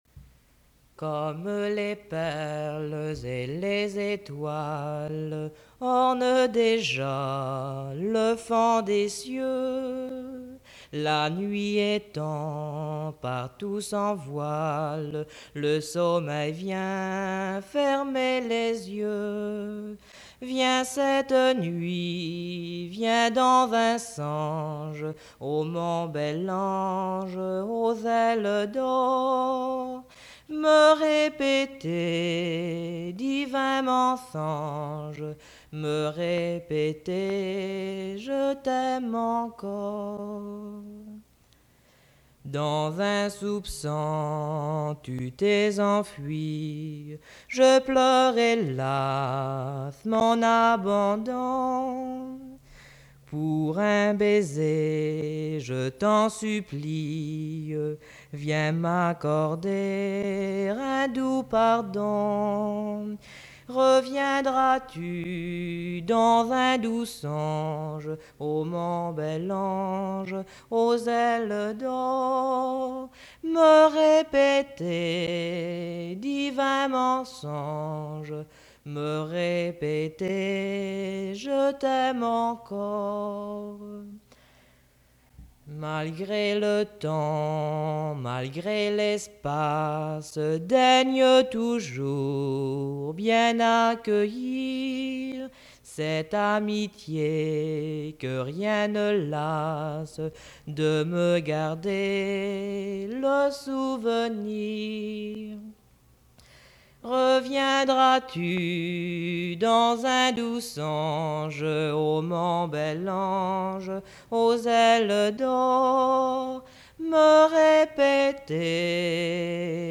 Emplacement Saint-Pierre